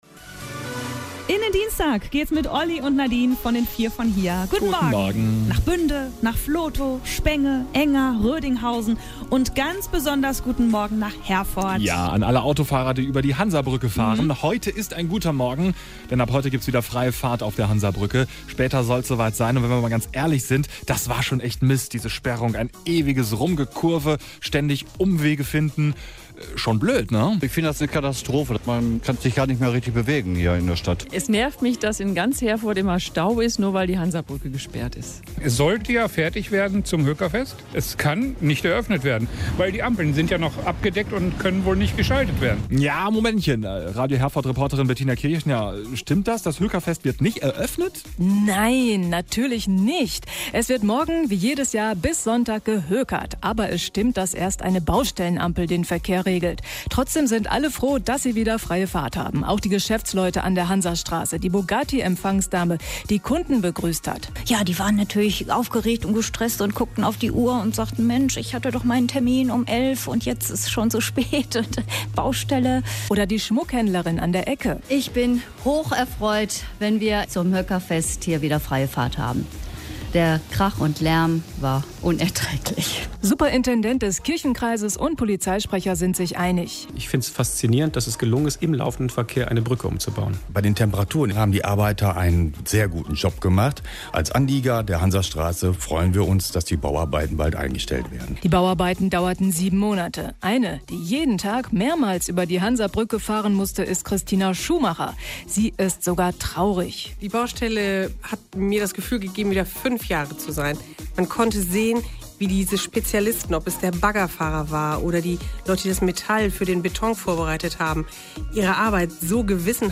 Ein paar Stimmen aus der Bevölkerung hat Radio Herford Ende Juni eingefangen und gesendet.
stimmen-zur-hansabruecke-1.mp3